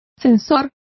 Complete with pronunciation of the translation of sensor.